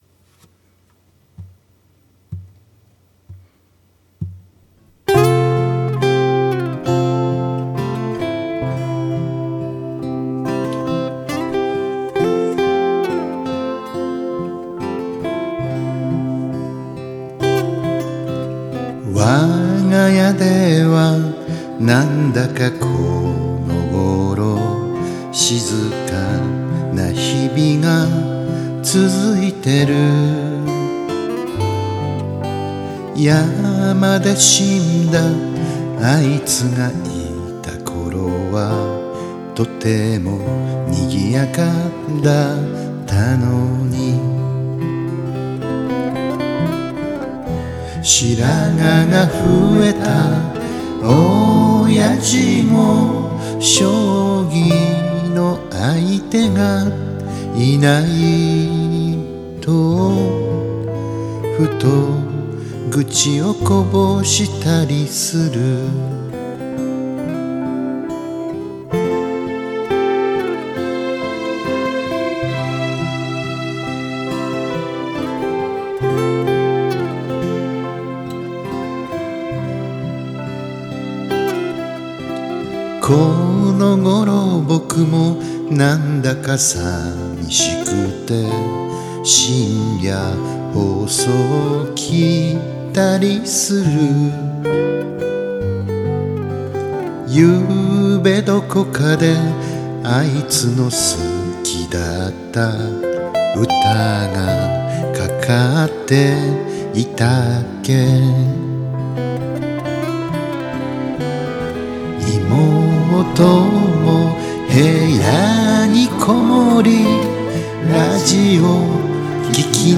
カバー曲　　青春時代の曲です
ＨＤ-28Ｖをｌｉｎｅ録りしています